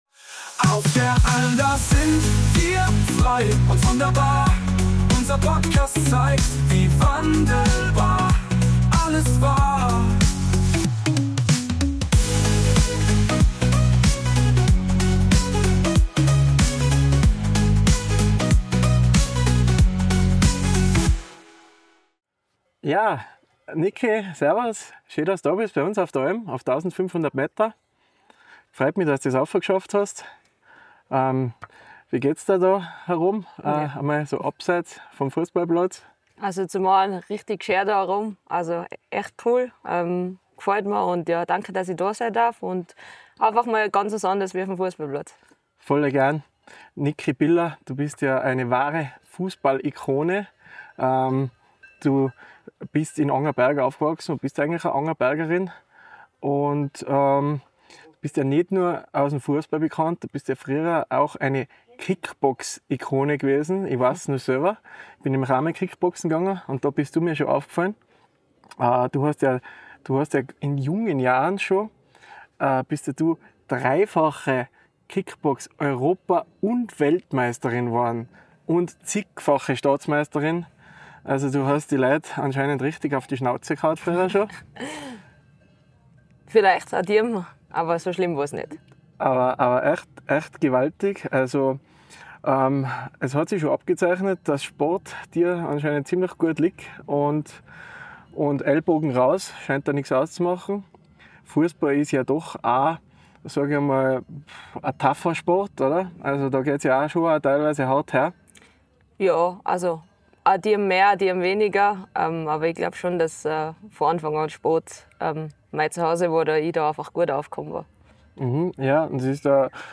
Ein ehrliches Gespräch über Motivation, Teamgeist und den Weg an die Spitze.